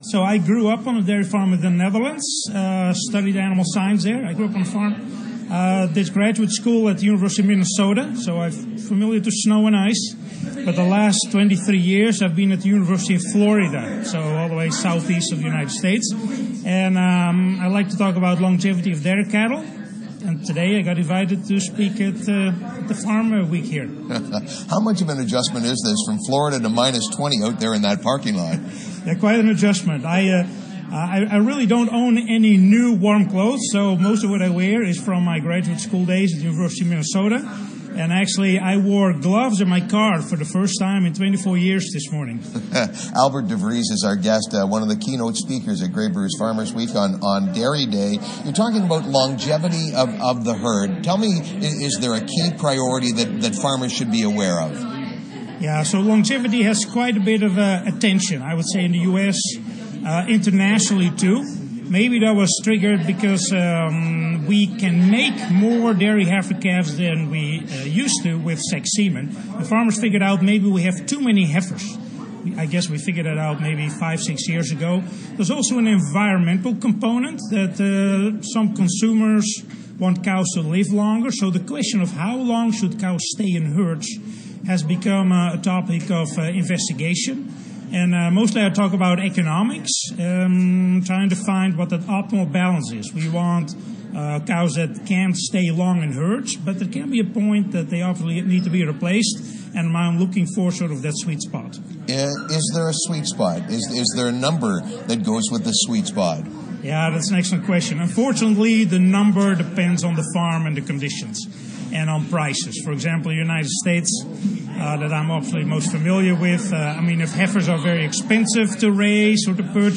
Dairy Herd Longevity. Interview
from Grey Bruce Farmers Week